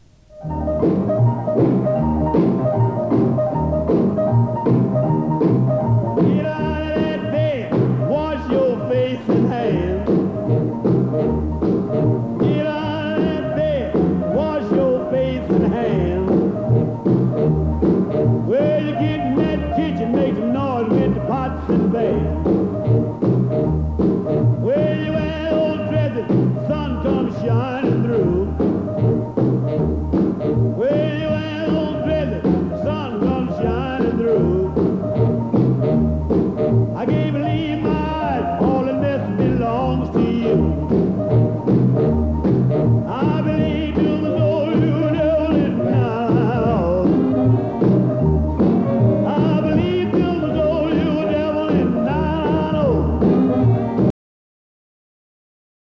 'shout blues'